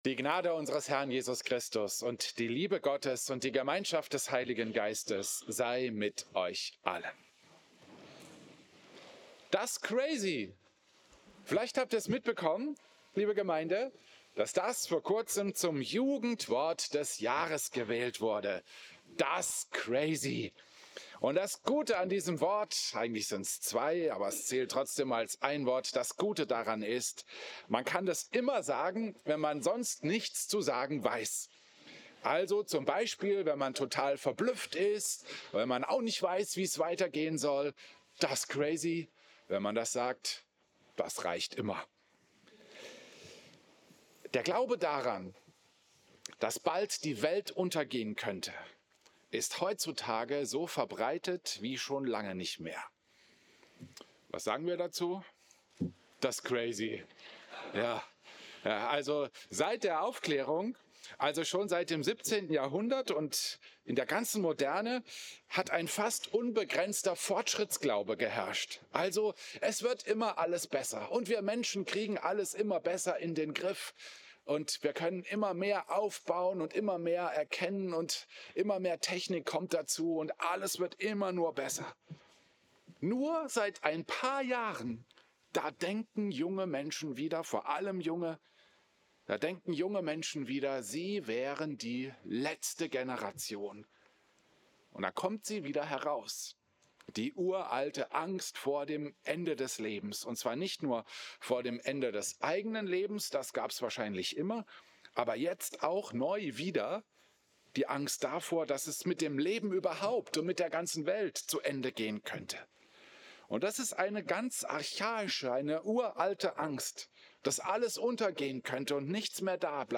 Predigt
Klosterkirche Volkenroda, 2.